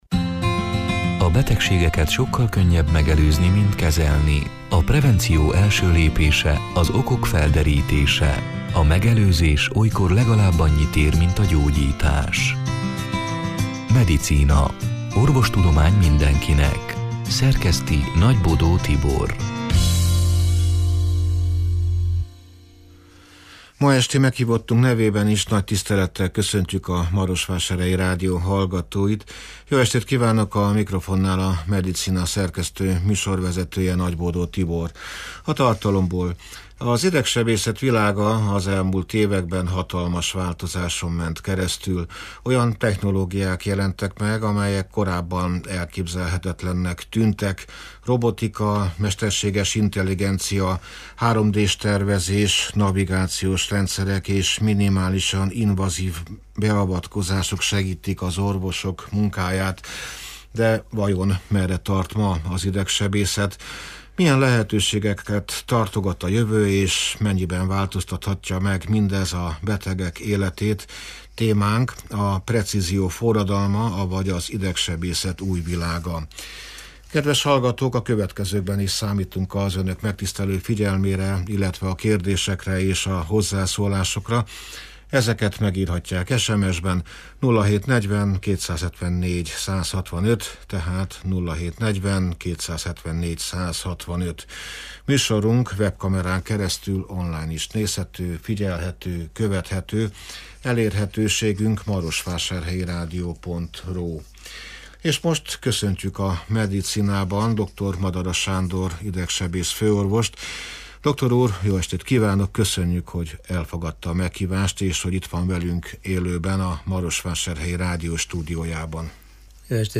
(elhangzott: 2026. április 22-én, szerdán este nyolc órától élőben)